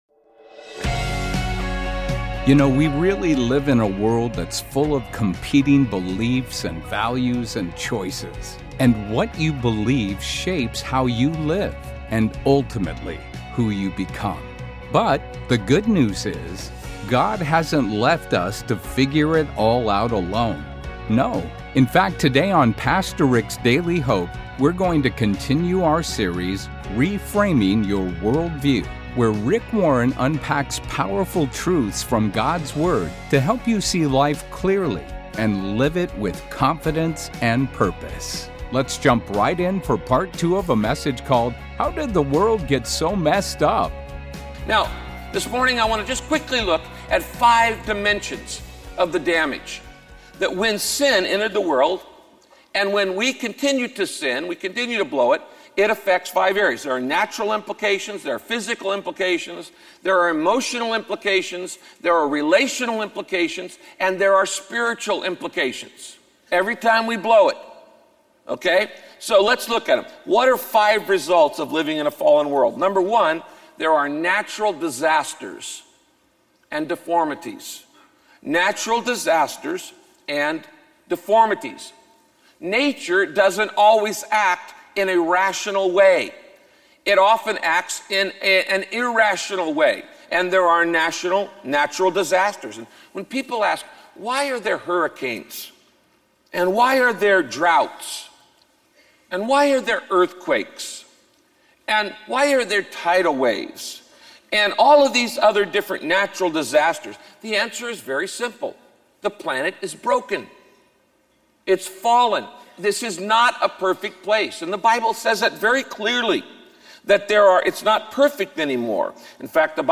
Why do we so often choose to ignore God and do the wrong thing? In this broadcast, Pastor Rick teaches the reasons why we sin, how it's affected not only us but…